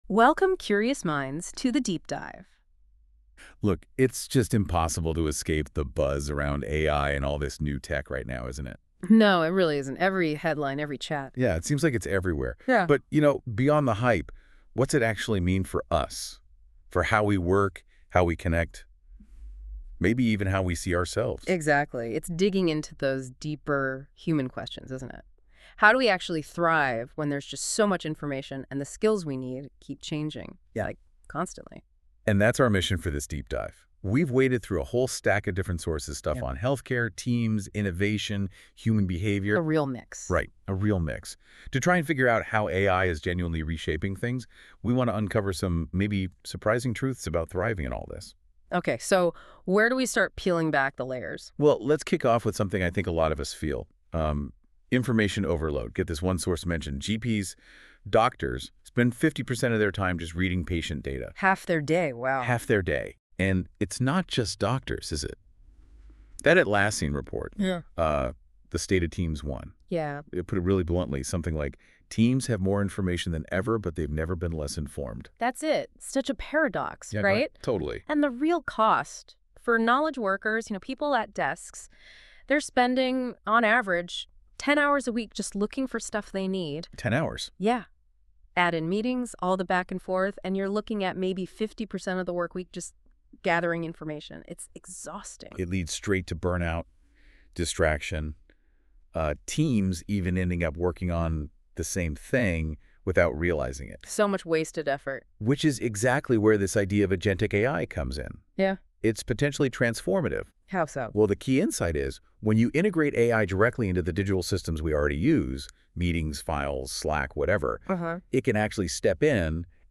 Listen to an AI audio overview of key insights